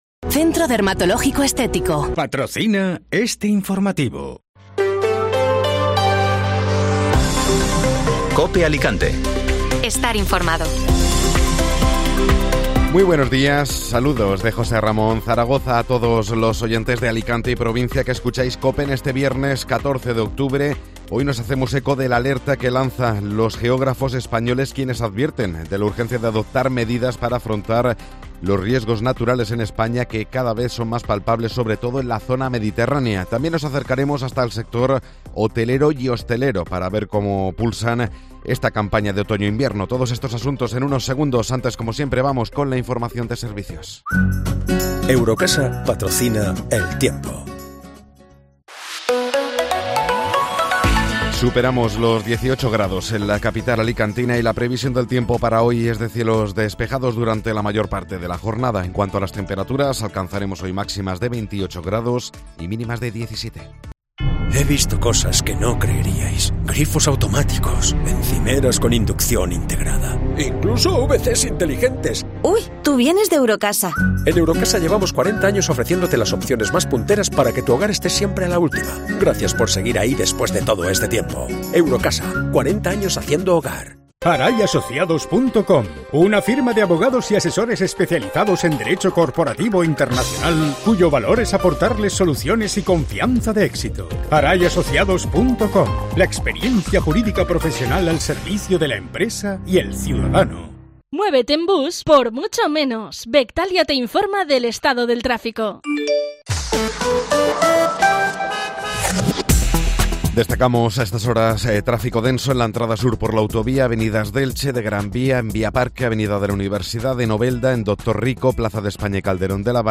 Informativo Matinal (Viernes 14 de Octubre)